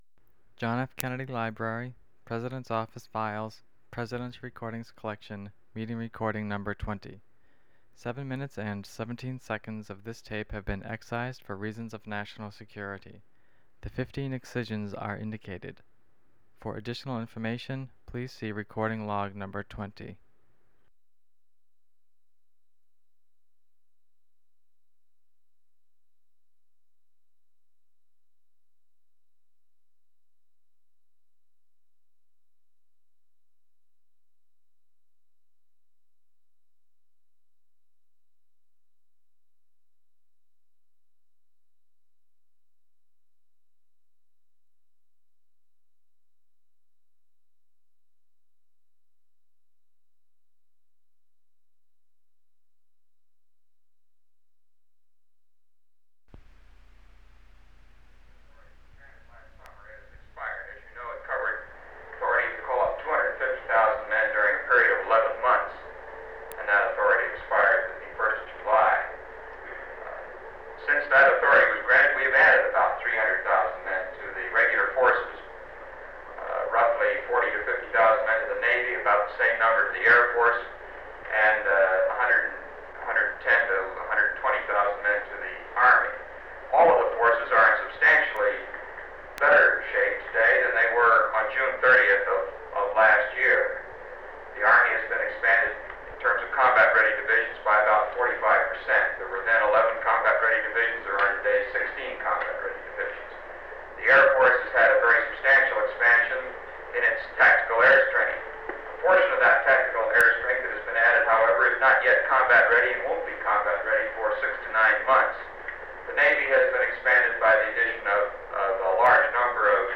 Secret White House Tapes | John F. Kennedy Presidency Meeting on the Congressional Resolution about Cuba Rewind 10 seconds Play/Pause Fast-forward 10 seconds 0:00 Download audio Previous Meetings: Tape 121/A57.